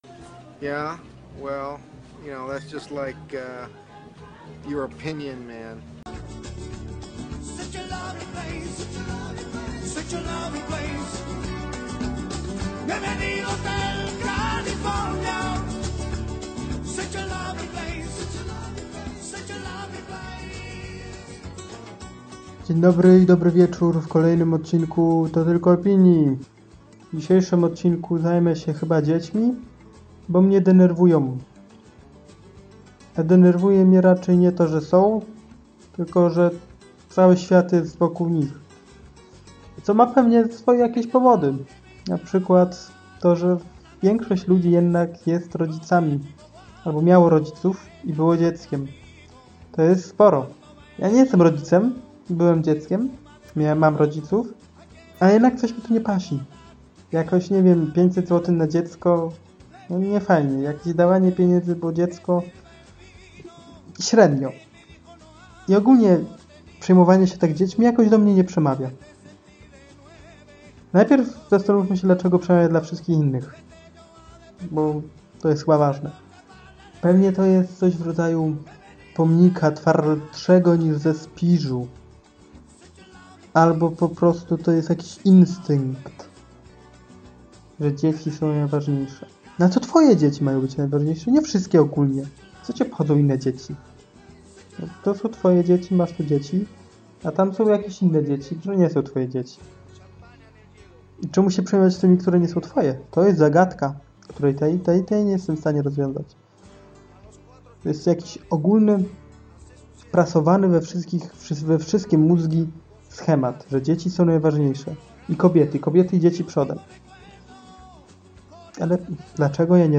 Mówię o swojej niechęci do dzieciokracji. Jak zwykle chaotycznie, tym razem także bełkotliwie.
Dobrze się słucha(good voice).